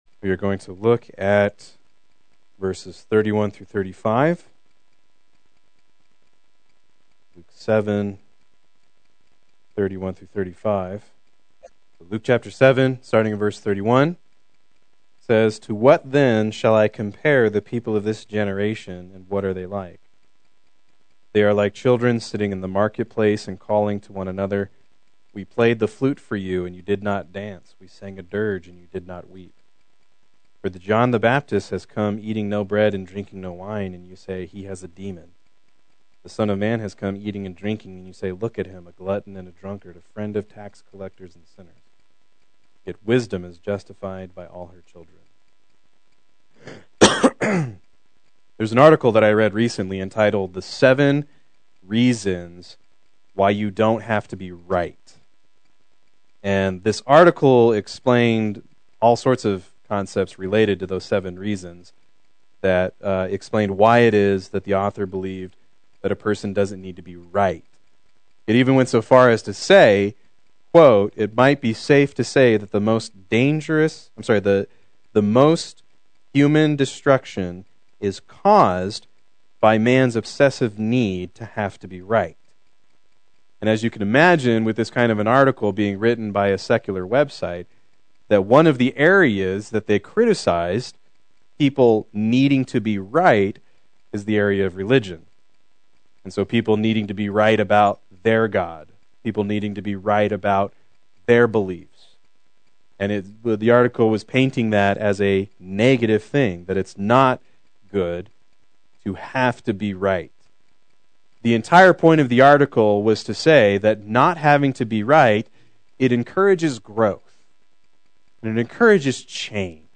Proclaim Youth Ministry - 08/17/18
Play Sermon Get HCF Teaching Automatically.